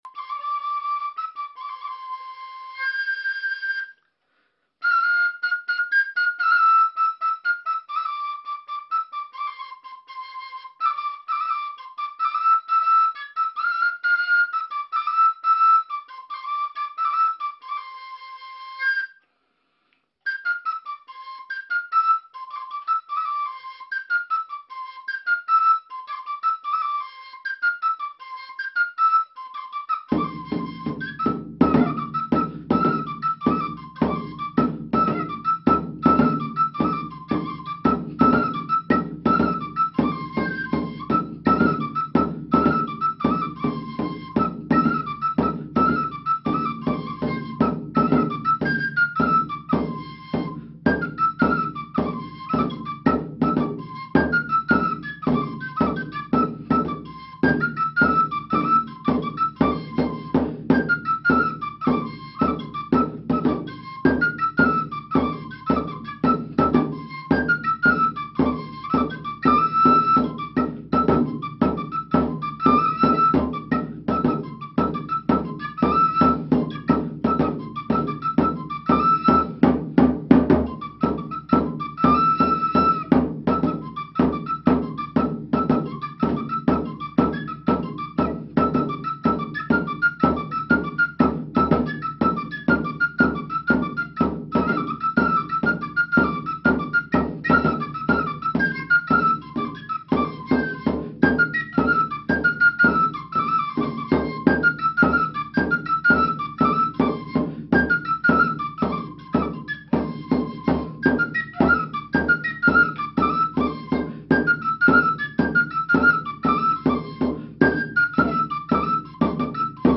Aquests forats estan disposats dos davant i un darrera, produint fins a 17 sons diferents.
Sulittu e tamburinu (Sardenya)
12-sulittu-e-tamburinu-sardenya.mp3